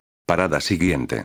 parada_siguiente.wav